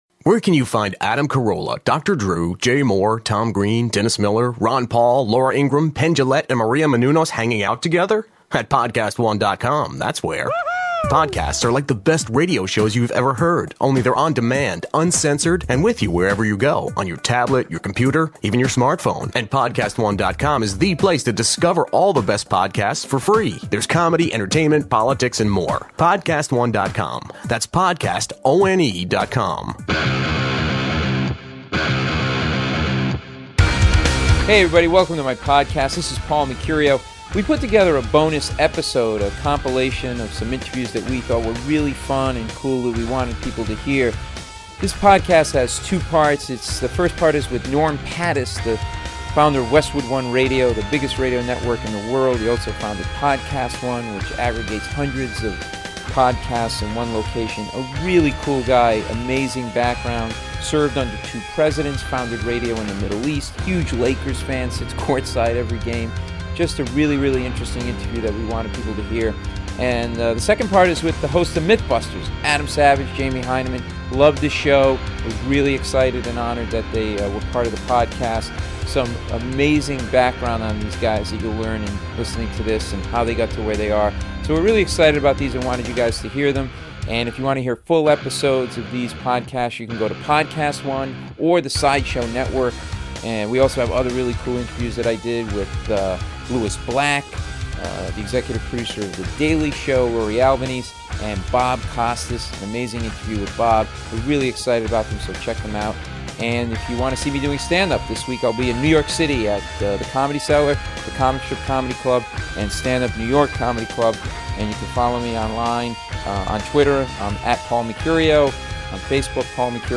Here is a bonus episode with highlights from my interviews with PodcastOne's Norm Pattiz and Adam Savage & Jamie Hyneman of Mythbusters.